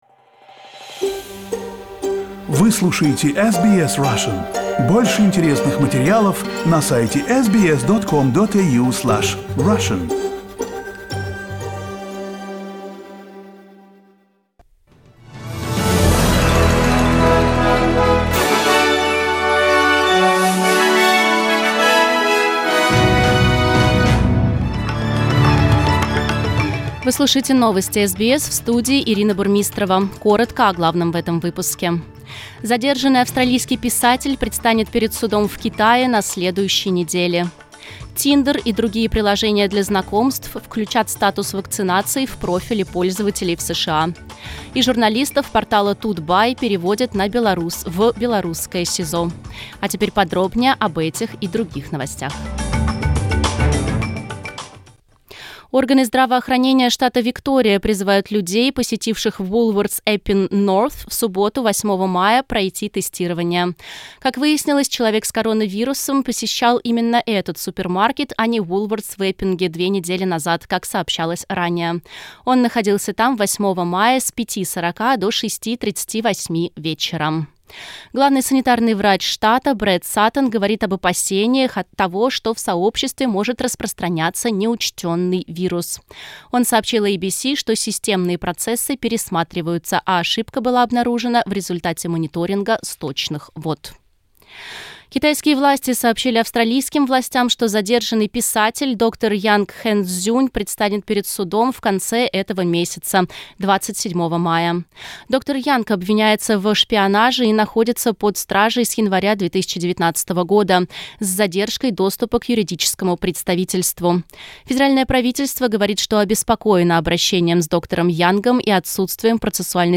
Новости SBS на русском языке - 22.05